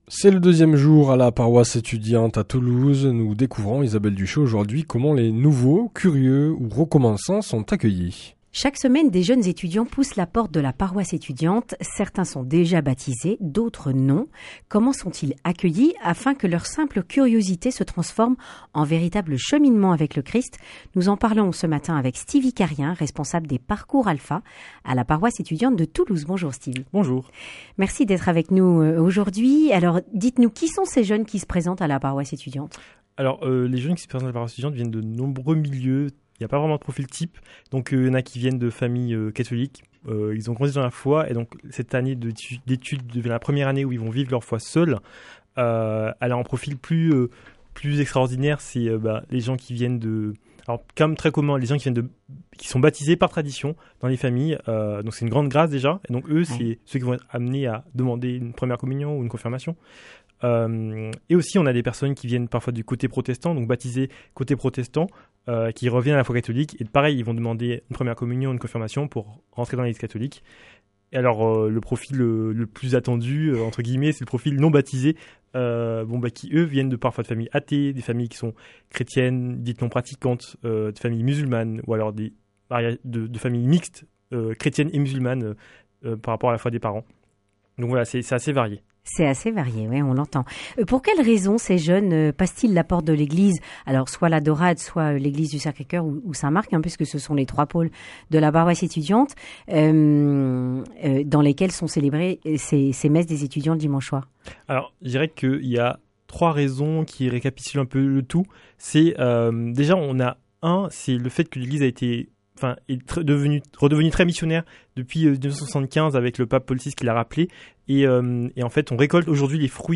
itv_vendredi_saint.mp3